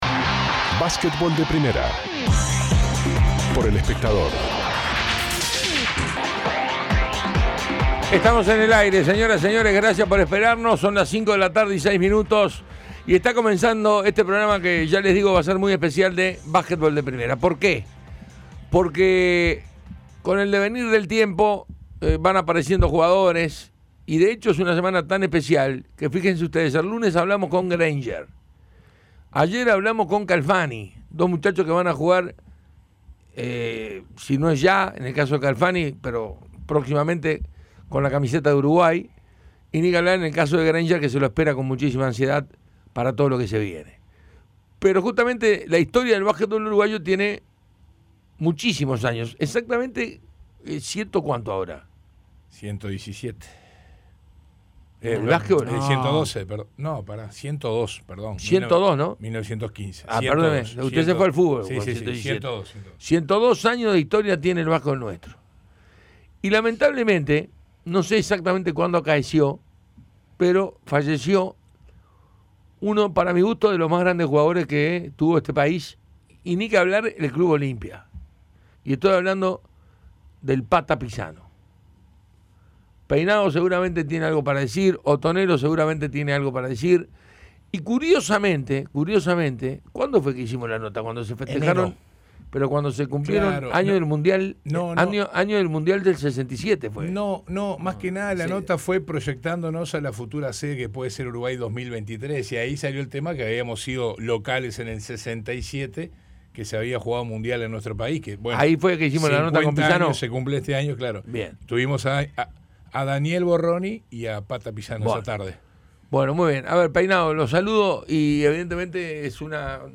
y además de un fragmento de la entrevista